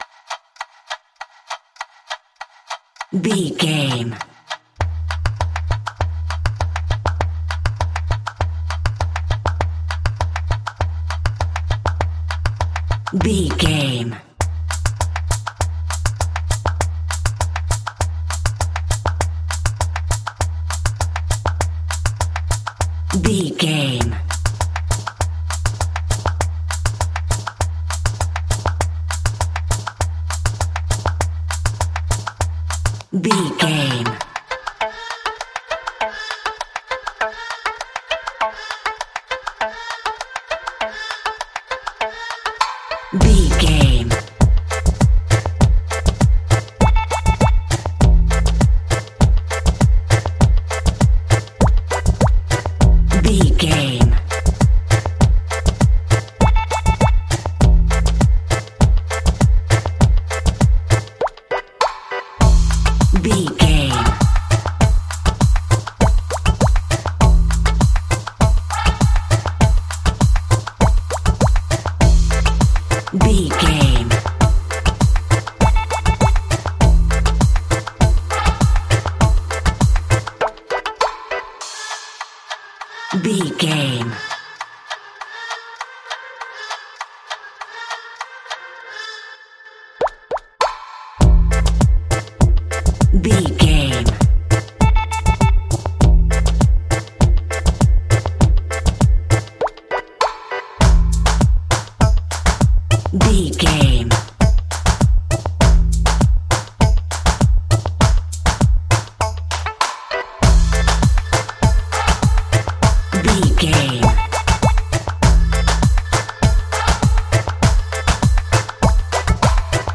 Aeolian/Minor
playful
tranquil
repetitive
percussion
electric piano